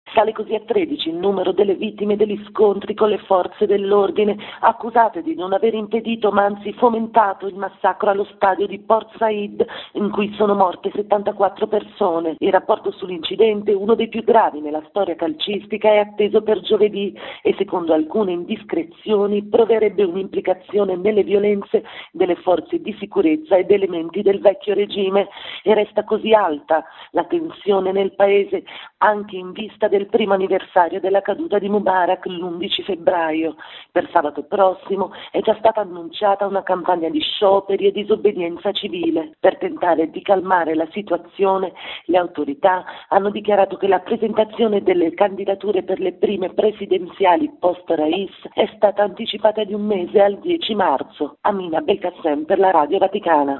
Al Cairo quinto giorno di scontri, nelle strade che costeggiano il ministero dell'Interno egiziano. Per calmare gli animi è stato anticipato di un mese, ovvero al 10 marzo, l'avvio delle registrazioni per i candidati che intendano partecipare alle elezioni presidenziali, ma ieri ancora un morto per gli scontri tra polizia e dimostranti per il massacro di Porto Said. Il servizio